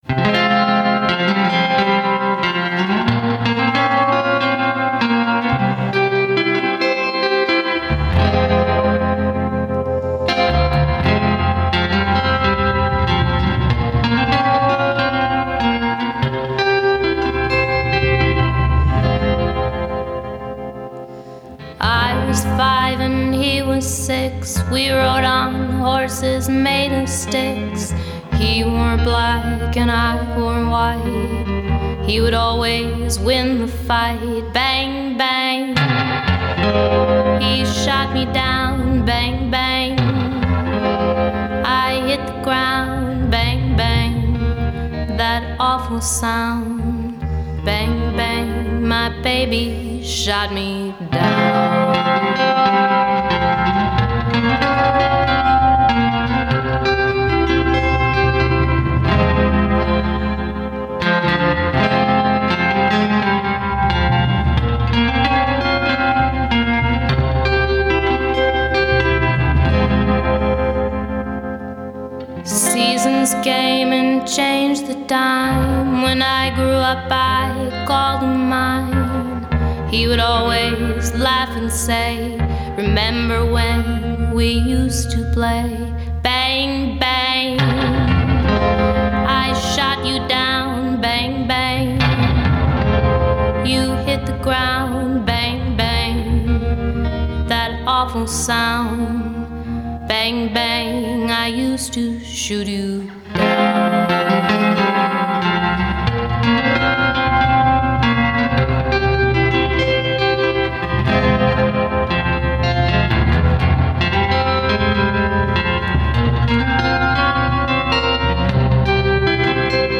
Review of the Twin Cities folk/country band's newest release
sunny, whimsical nature
guitar